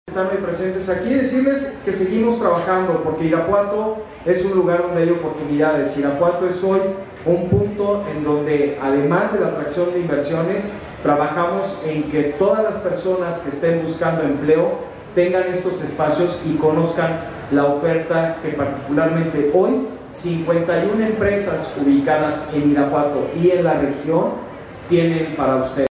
AudioBoletines
Héctor Muñoz Krieger, director de economía